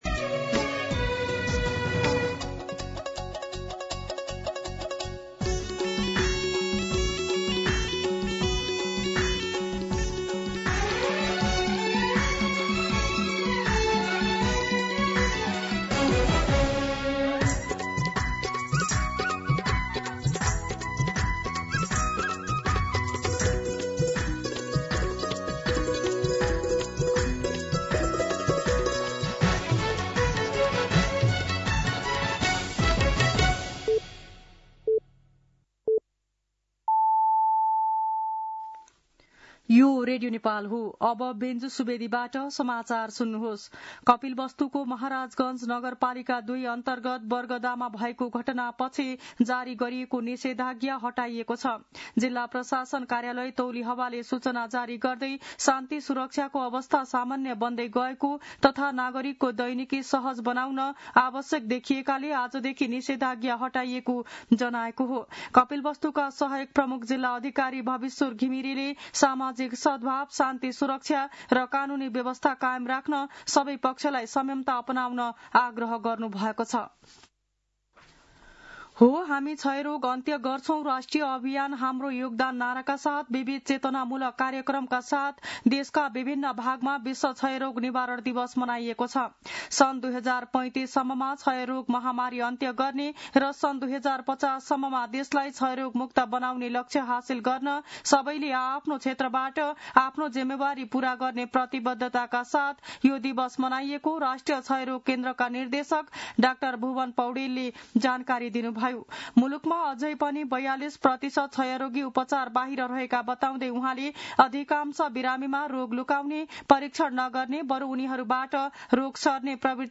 दिउँसो १ बजेको नेपाली समाचार : १० चैत , २०८२